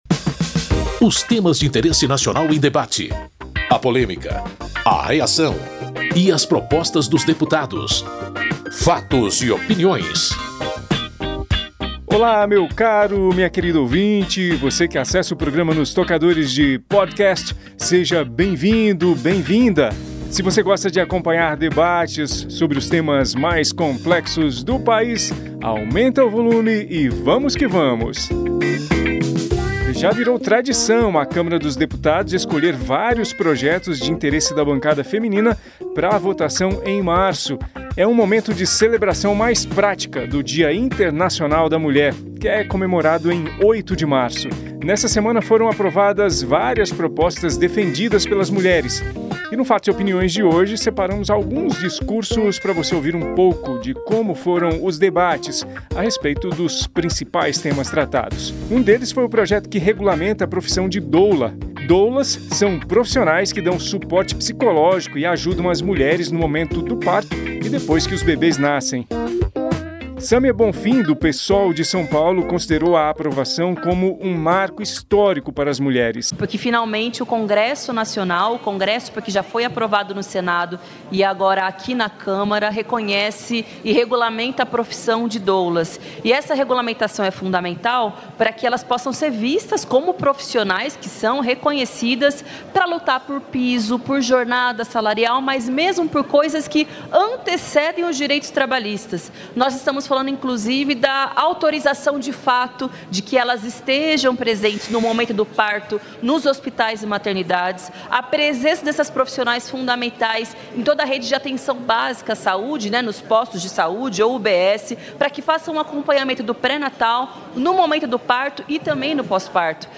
Nesta edição do Fatos e Opiniões, separamos discursos sobre vários dos projetos votados em homenagem às mulheres nesta semana.